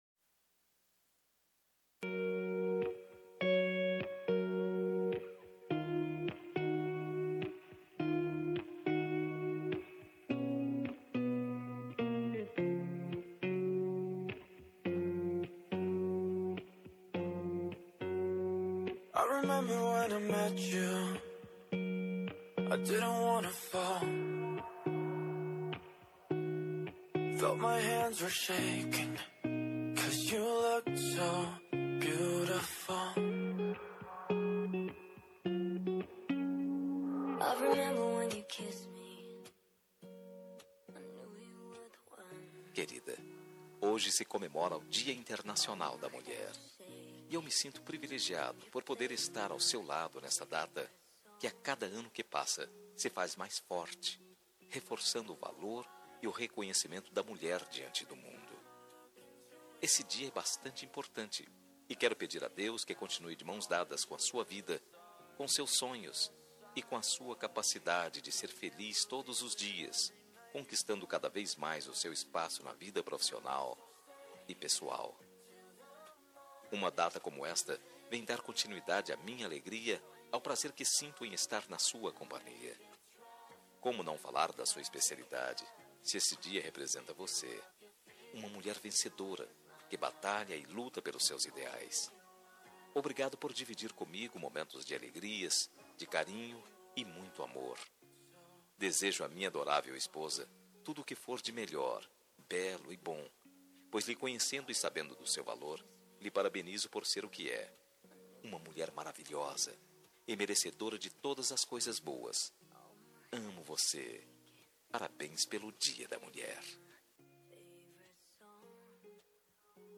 Dia das Mulheres Para Namorada – Voz Masculina – Cód: 5310